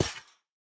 Minecraft Version Minecraft Version snapshot Latest Release | Latest Snapshot snapshot / assets / minecraft / sounds / block / spawner / step1.ogg Compare With Compare With Latest Release | Latest Snapshot